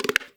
Perc (OGKush2).wav